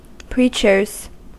Ääntäminen
Ääntäminen US Haettu sana löytyi näillä lähdekielillä: englanti Käännöksiä ei löytynyt valitulle kohdekielelle. Preachers on sanan preacher monikko.